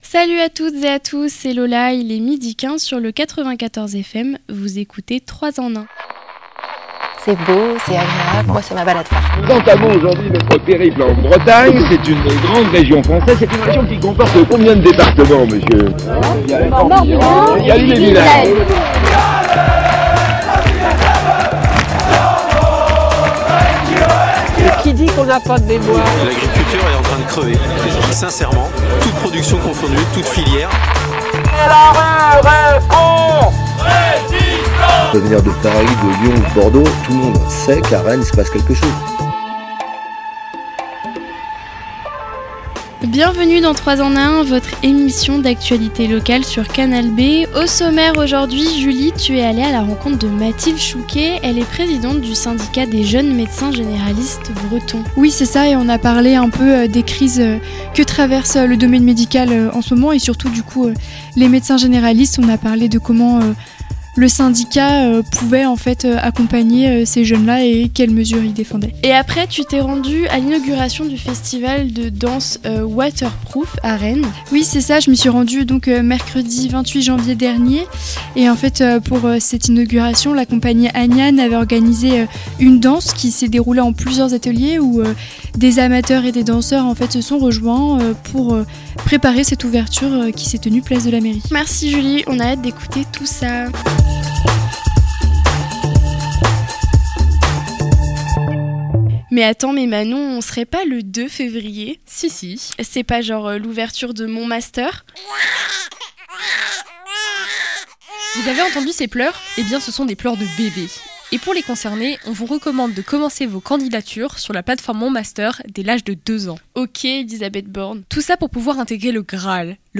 La semaine dernière, la radio s'est rendue aux répétitions du spectacle “Ces gens qui restent” .
C’est sous une pluie fine que professionnel·le·s, amateur·ice·s et simples curieu·ses·x se sont réuni·e·s pour investir la place de la mairie le temps d’une danse.